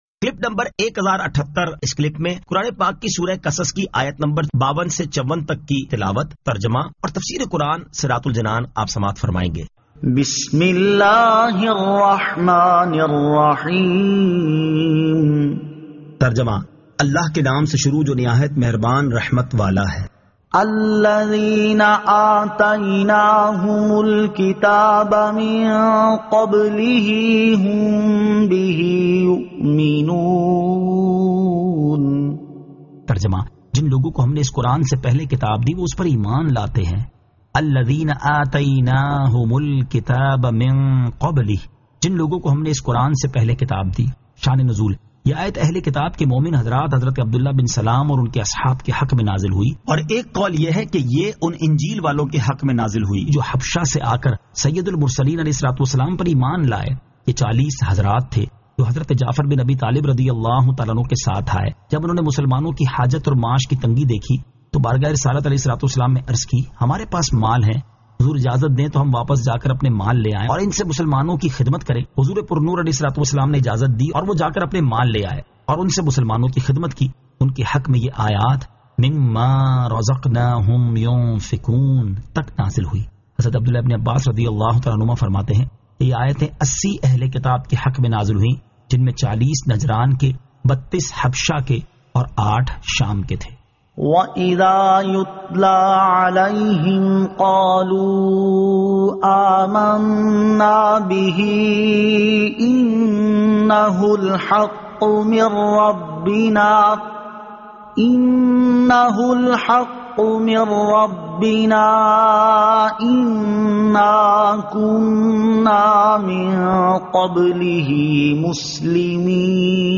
Surah Al-Qasas 52 To 54 Tilawat , Tarjama , Tafseer